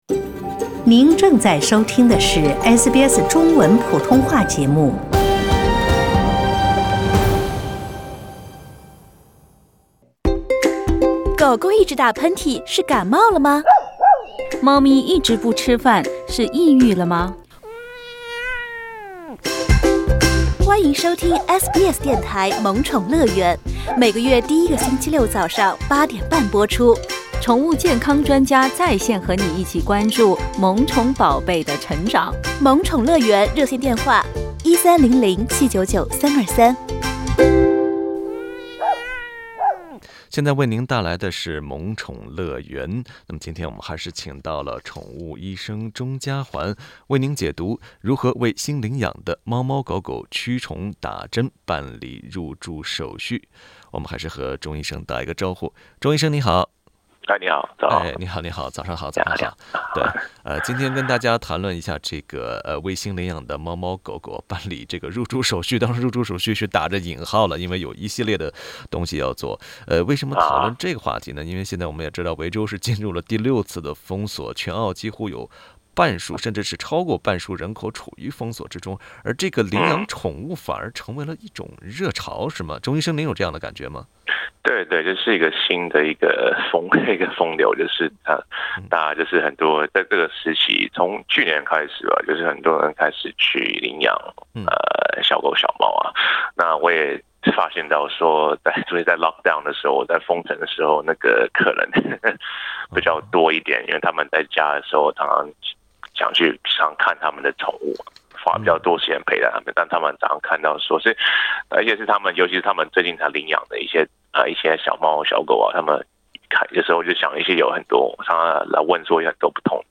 疫情封锁导致猫狗领养数激增，但您知道如何为家里稚嫩的新成员办理好“入住手续”，您知道该怎么带它们驱虫、接种疫苗、备好居家小窝吗？（点击图片音频，收听采访）